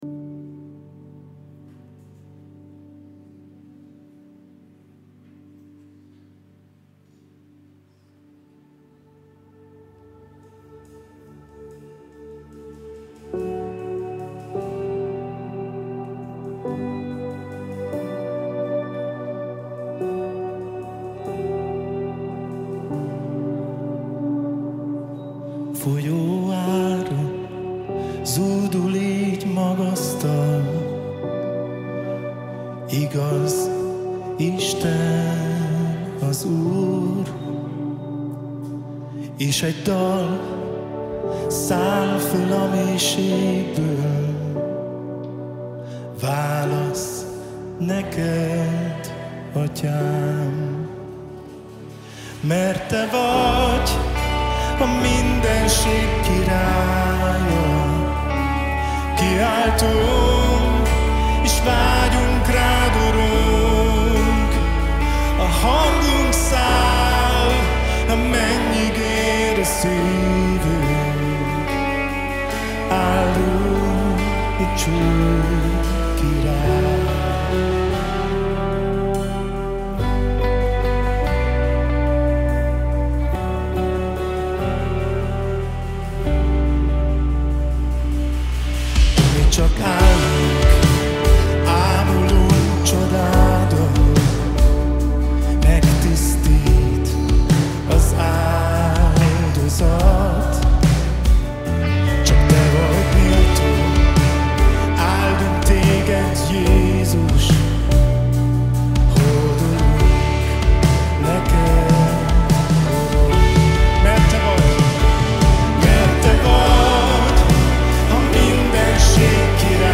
Live Session